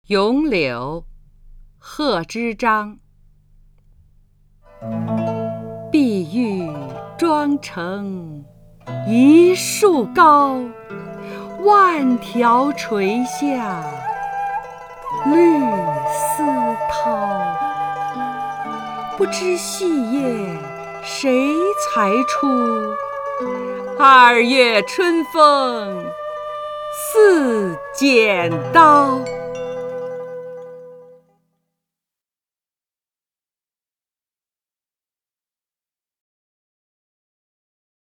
张筠英朗诵：《咏柳》(（唐）贺知章) （唐）贺知章 名家朗诵欣赏张筠英 语文PLUS